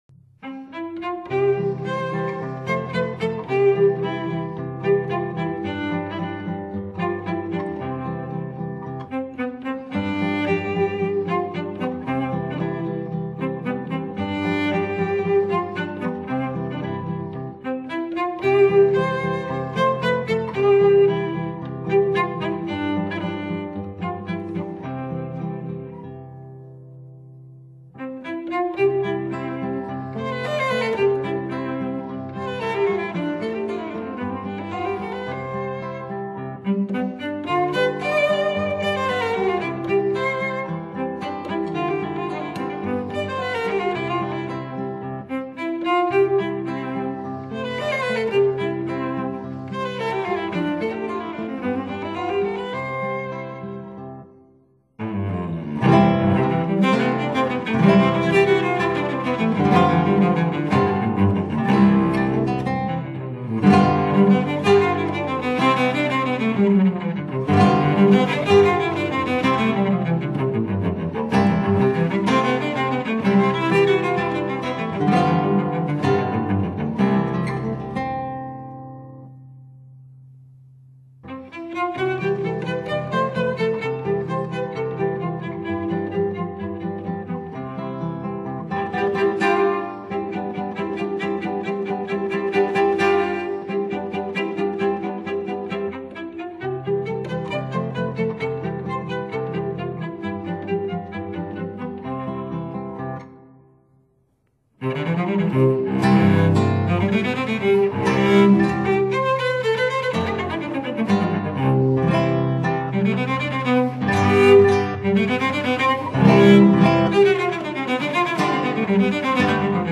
演奏團體：大提琴/吉他
violoncello
guitar
音色優美細緻，吉他聲粒粒脆，神髓盡現。